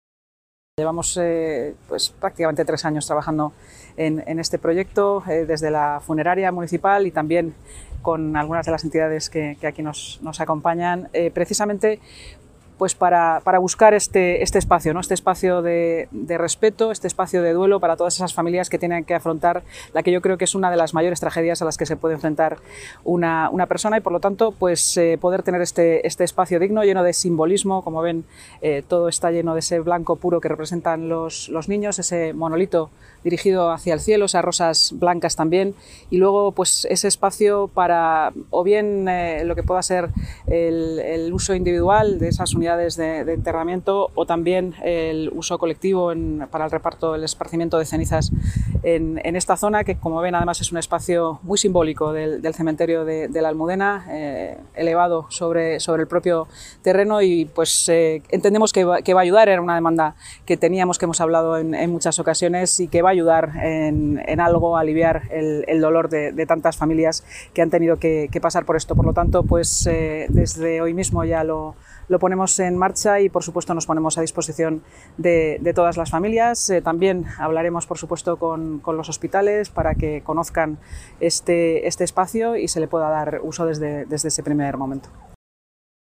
Nueva ventana: Intervención de la vicealcaldesa y delegada de Seguridad y Emergencias, Inma Sanz, durante su visita al parque de Mariposas en el Cementerio de la Almudena: